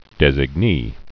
(dĕzĭg-nē)